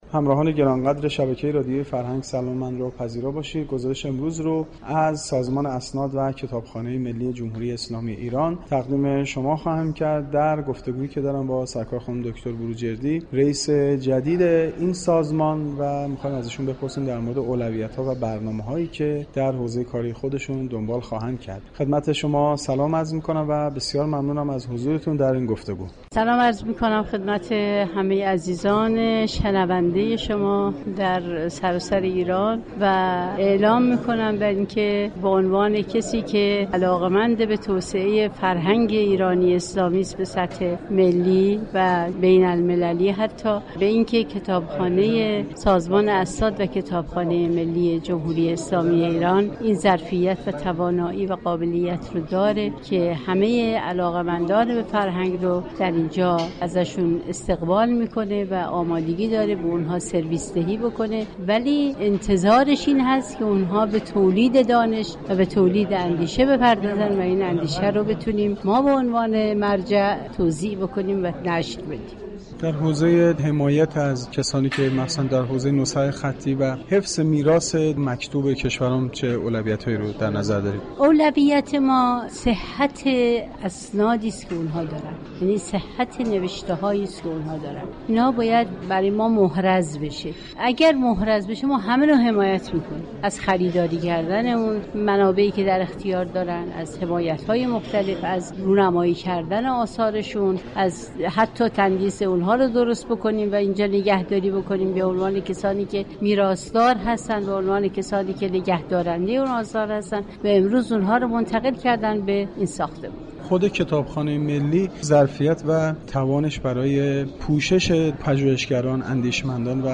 دكتر اشرف بروجردی ، رییس سازمان اسناد و كتابخانه ی ملی جمهوری اسلامی ایران در گفتگو با گزارشگر رادیو فرهنگ، درباره ی اولویتها و برنامه های آینده این سازمان گفت : سازمان اسناد و كتابخانه ی ملی جمهوری اسلامی ایران از همه ی علاقمندان به فرهنگ و ادب استقبال كرده و تمامی امكانات لازم را در اختیار آنها قرار می دهد و در مقابل این سازمان انتظار دارد تا علاقمندانِ فرهنگ و اادب و اندیشمندان نیز به تولید دانش و اندیشه پرداخته تا كتابخانه ی ملی این اندیشه و دانش را به عنوان مرجع منتشر كند .